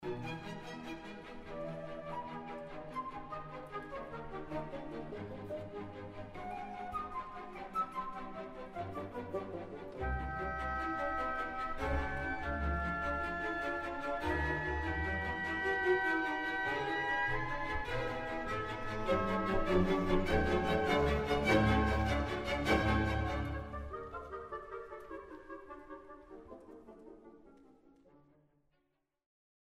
The real stand-out feature of this movement is the way the woodwind-dominated trio section contrasts with the stormy G minor of the Scherzo.
The lightness of touch and rhythmic energy, complete with pizzicato violins accompanying the woodwind, are more reminiscent of Mendelssohn than Beethoven.
Example 5 – Opening of Trio: